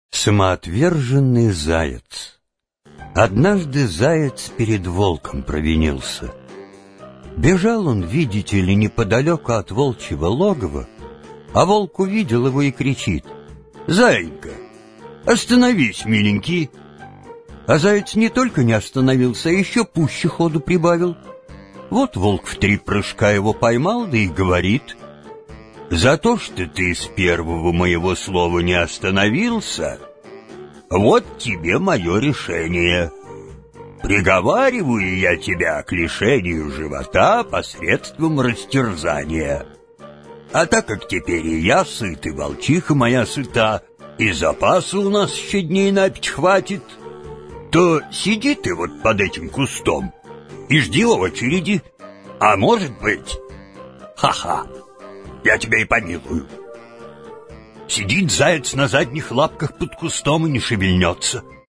Аудиокнига Сказки | Библиотека аудиокниг